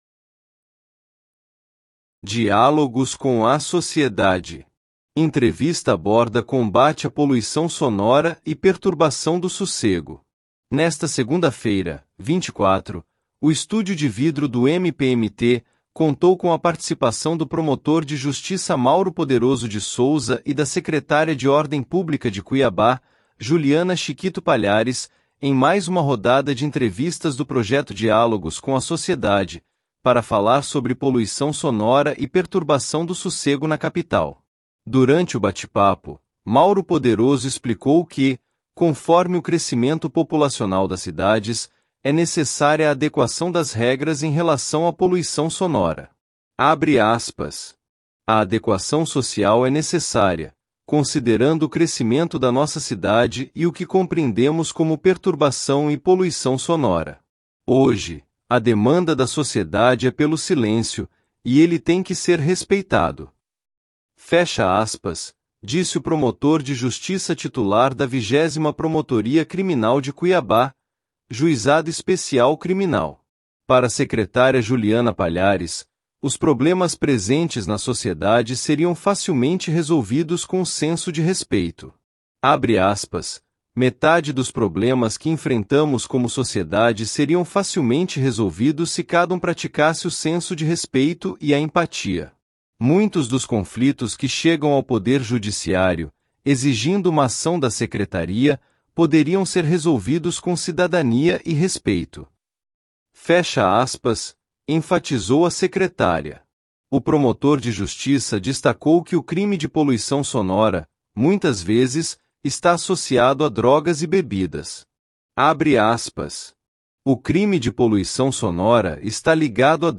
Entrevista aborda combate à poluição sonora e perturbação do sossego
Nesta segunda-feira (24), o estúdio de vidro do MPMT contou com a participação do promotor de Justiça Mauro Poderoso de Souza e da secretária de Ordem Pública de Cuiabá, Juliana Chiquito Palhares, em mais uma rodada de entrevistas do projeto “Diálogos com a Sociedade”, para falar sobre poluição sonora e perturbação do sossego na capital.